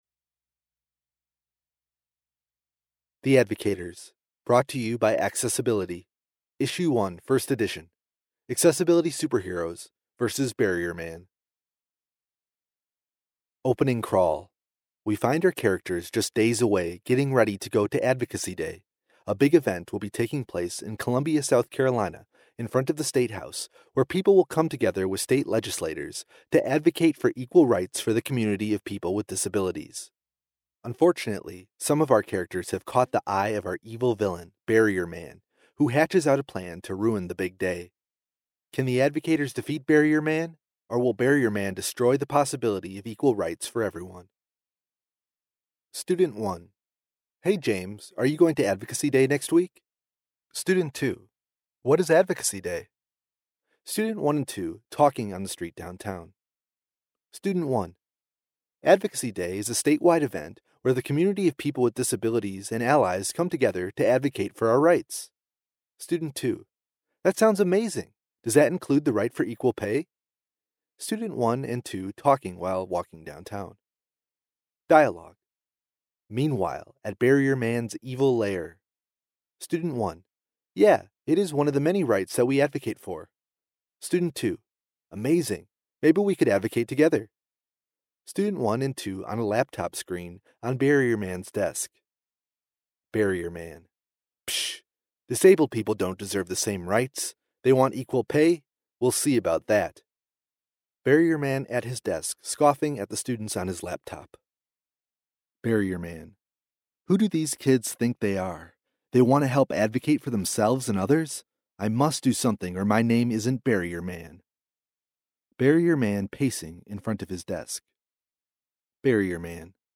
the-advocators-audiobook